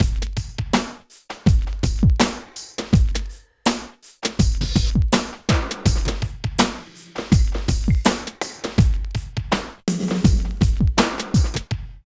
34 LOOP   -L.wav